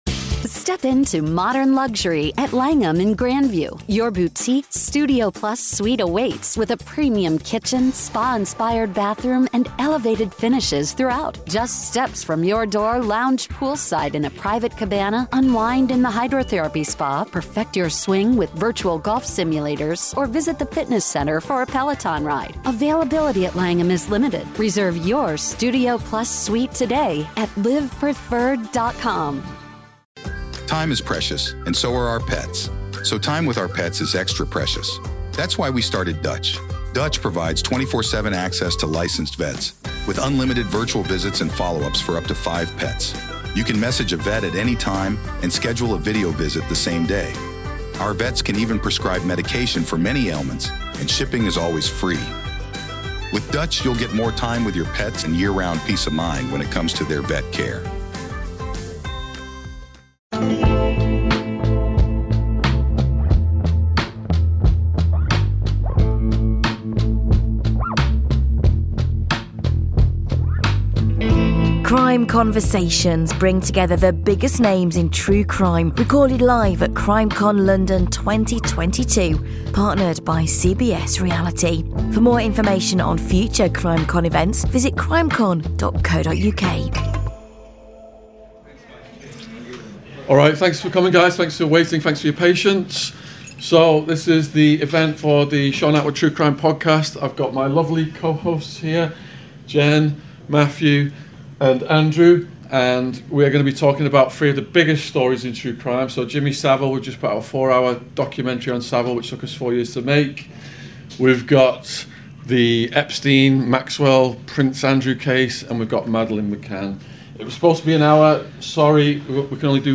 Epstein, McCann & Jimmy Savile CrimeCon London 2022: Epstein Files 3
interview